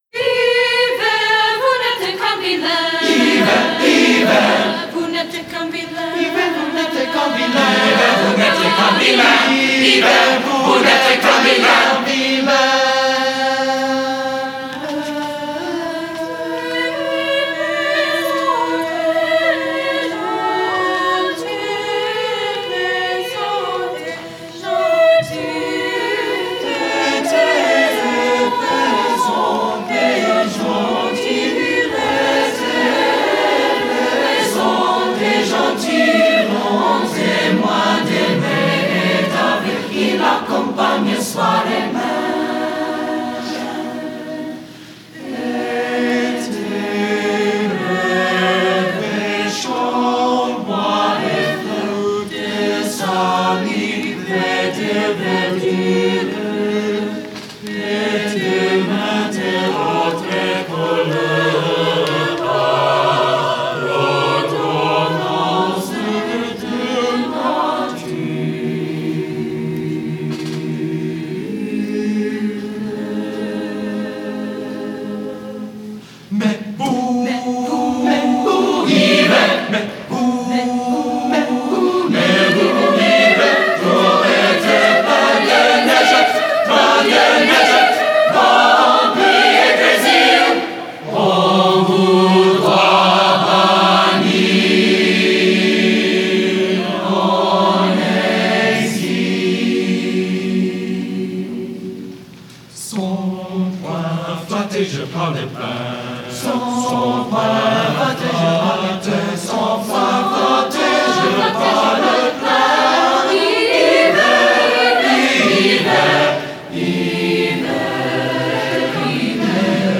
Spring Music Festival
Brookline High School Roberts/Dubbs Auditorium, Brookline, MA